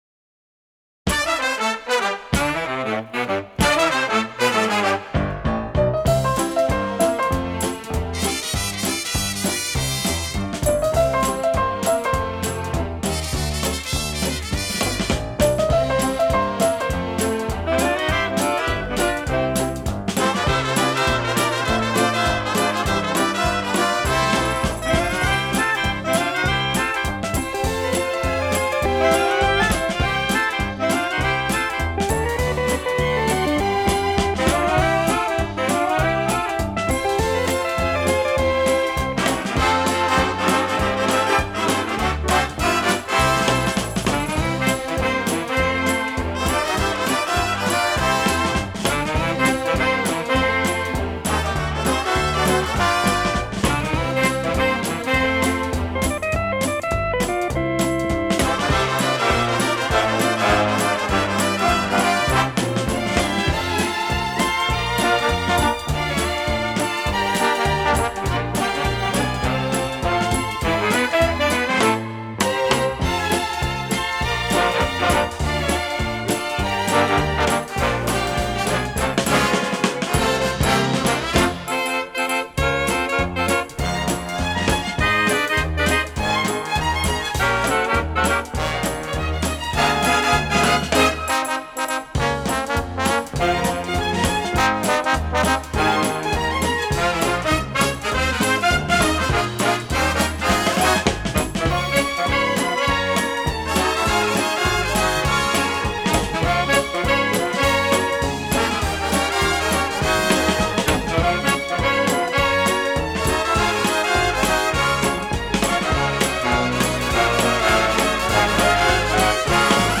此系列为国际标准舞曲，区别于一般交谊舞曲，曲子较短，仅适合比赛专用。